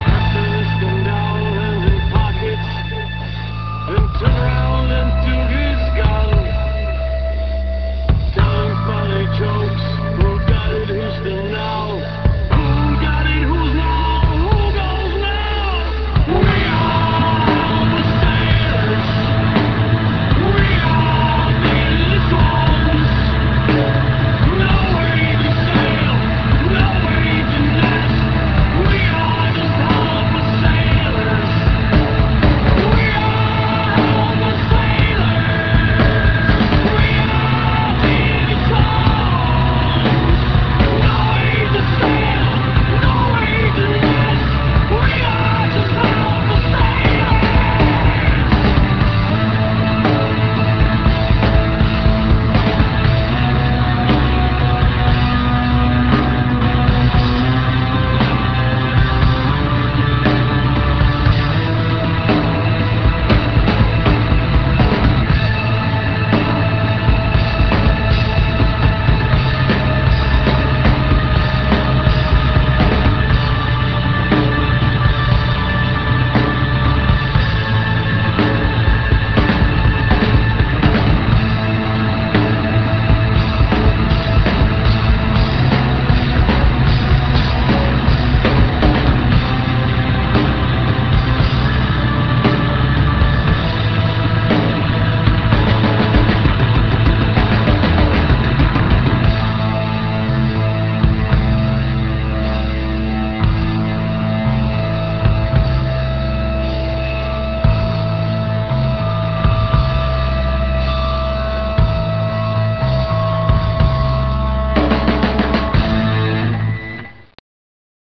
243 kB MONO